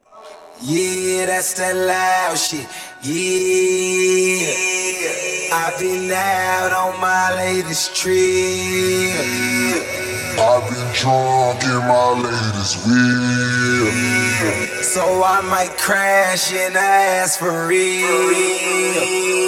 Не похоже на дабл. Скорее, расширялка, потому что в моно голос складывается с приличными потерями.
Есть ещё лёгкая модуляция - это или хорус сверху, или часть этой же обработки.
Вложения 1_rmmedia_(Vocals).mp3 1_rmmedia_(Vocals).mp3 763,3 KB · Просмотры: 429